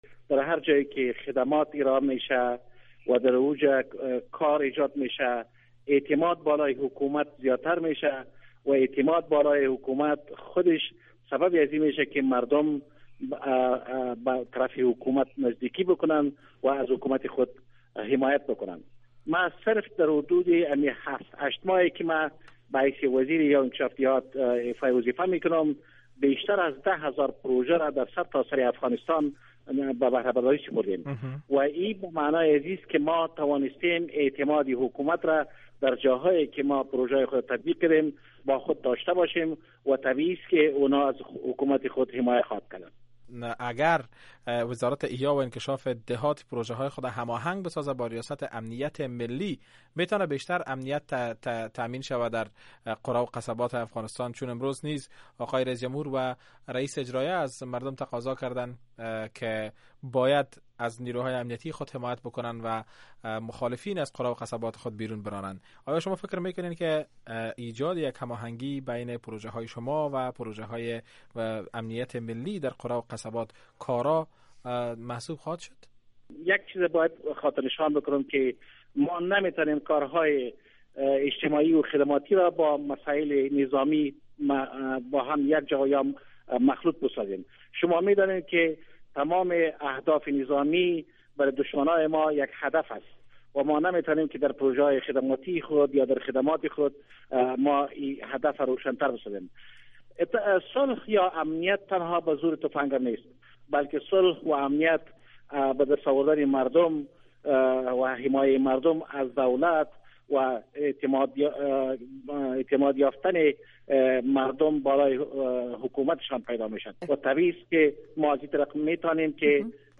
شرح بیشتر مصاحبه با نصیر احمد درانی را از اینجا بشنوید: